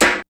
kits/RZA/Snares/WTC_SNR (17).wav at 32ed3054e8f0d31248a29e788f53465e3ccbe498